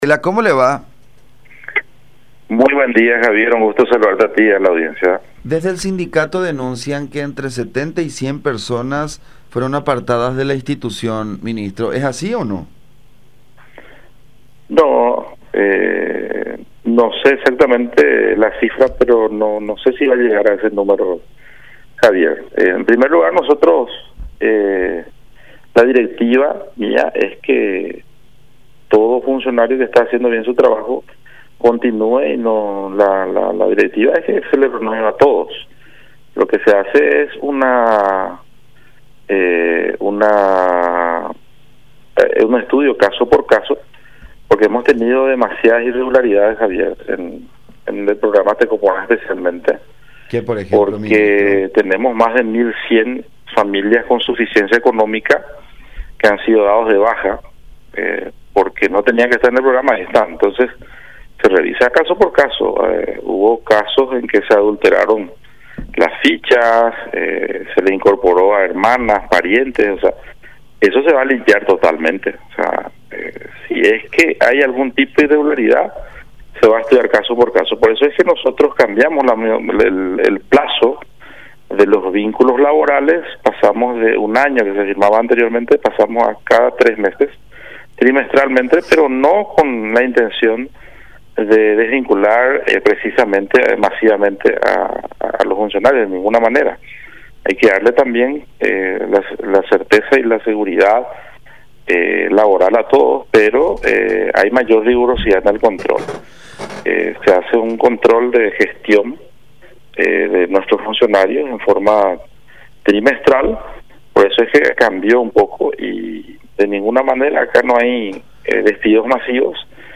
“Algunos adulteraron las fichas, les incorporaron a parientes, hijos… Eso ahora se va a limpiar totalmente”, explicó Varela en contacto con La Unión.